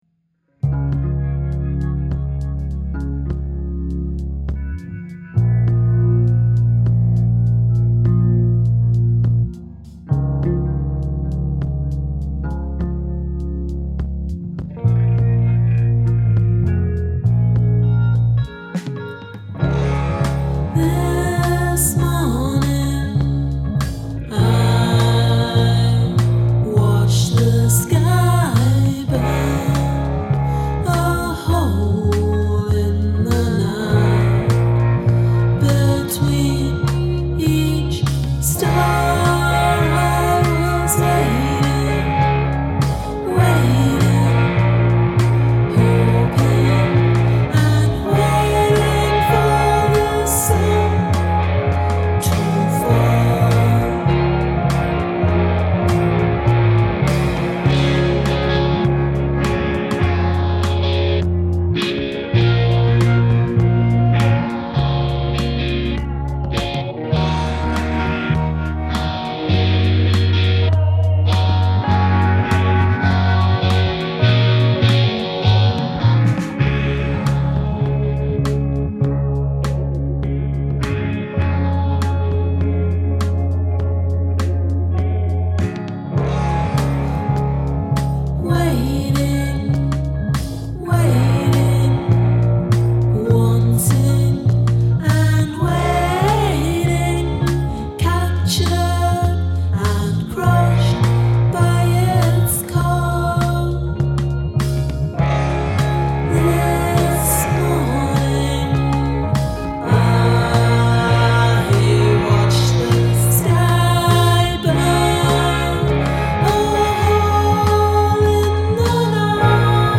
Great dynamics, this song really swells.